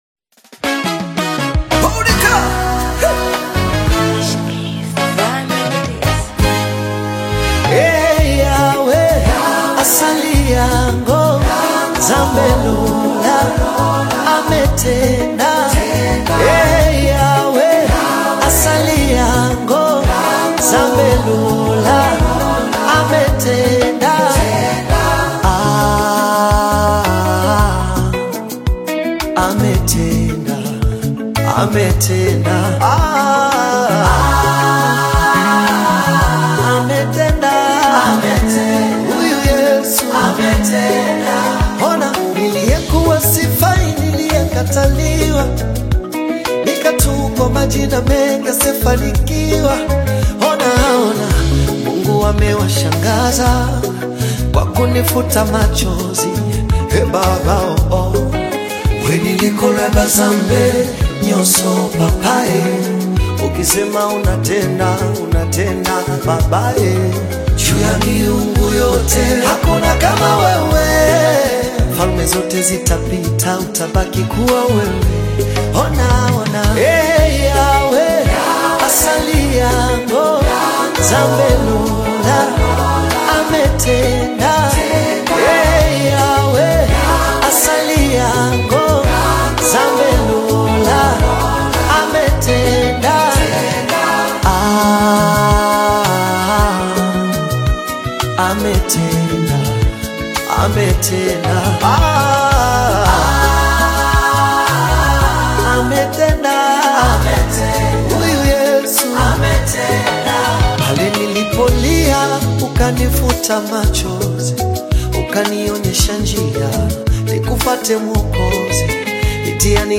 Genre: Tanzania Gospel Songs